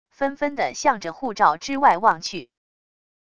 纷纷地向着护罩之外望去wav音频生成系统WAV Audio Player